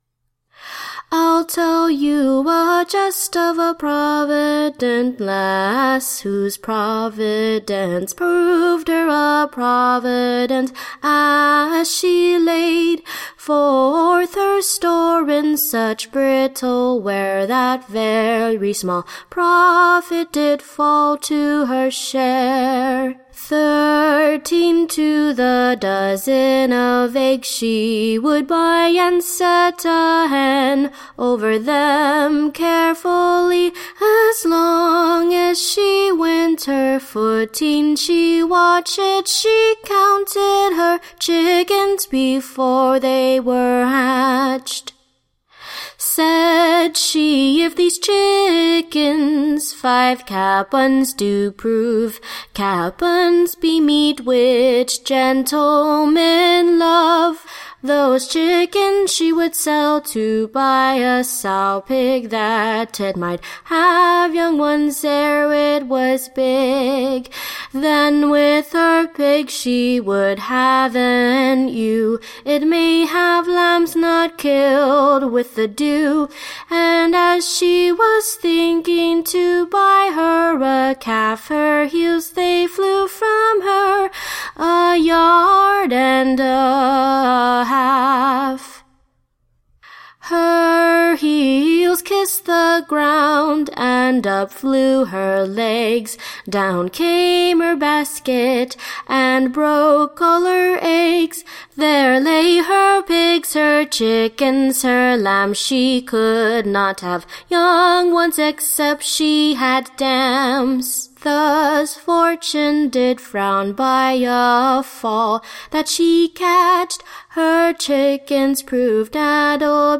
Recording Information Ballad Title THE / Young-Man & Maidens Fore-cast; / SHEWING HOW / They Reckon'd their Chickens before they were Hatcht. Tune Imprint To the Tune of, The Country Farmer.